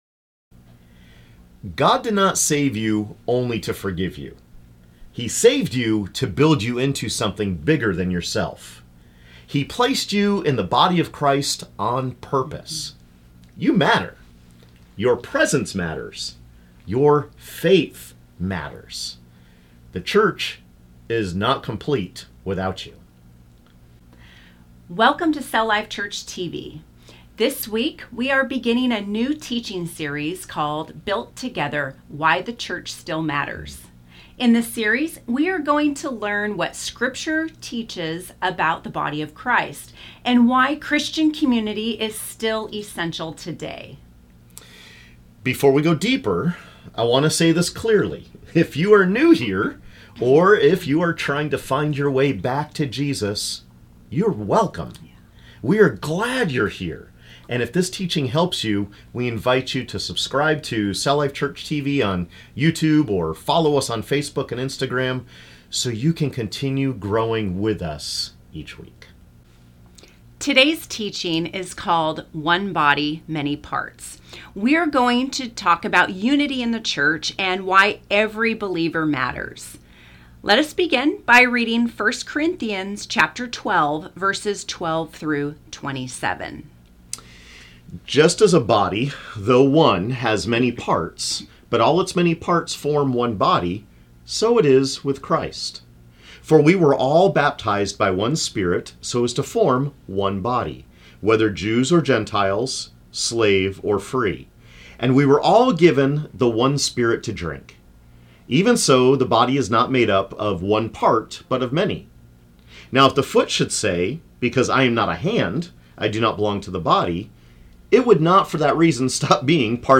In this teaching, we will learn why unity does not mean we all must be the same, and why God’s design includes many parts working together as one.